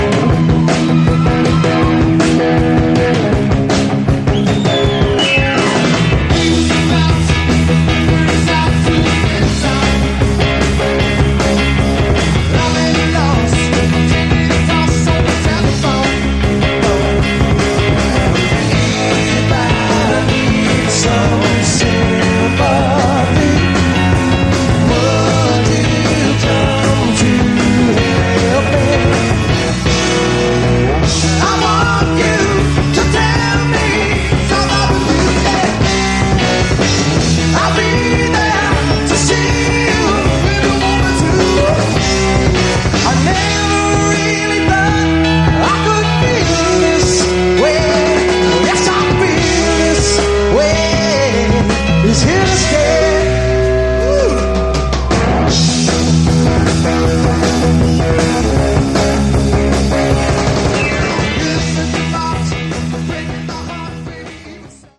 Category: Pomp/AOR
vocals, guitar, keyboards
vocals, bass
drums